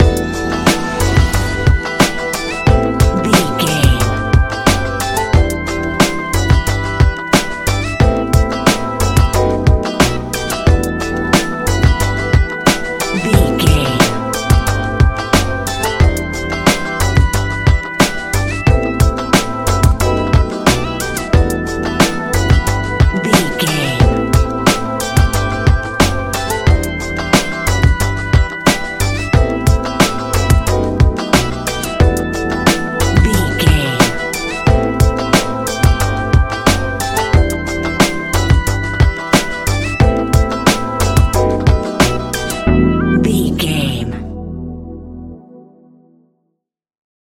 Ionian/Major
E♭
chilled
laid back
Lounge
sparse
new age
chilled electronica
ambient
atmospheric
morphing